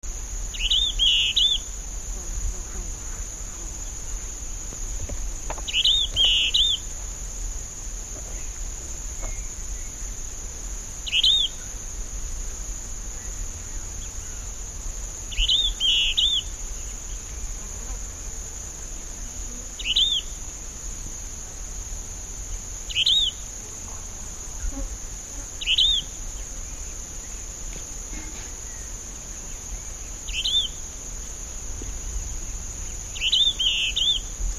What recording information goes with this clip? Dawn song after playback recorded Aguara-Ñu, Mbaracayú Biosphere Reserve, Departamento Canindeyú